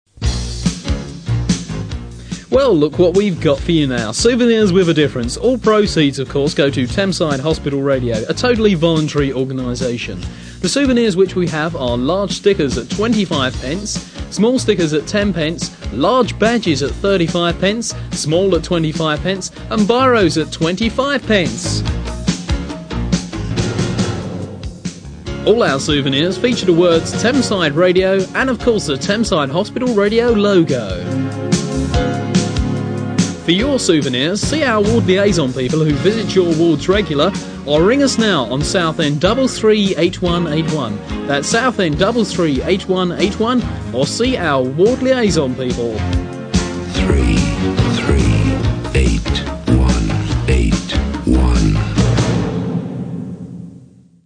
Some audio clips of old jingles and promotions as broadcast on Thameside Hospital Radio.
thamesidemerchandisepromo.mp3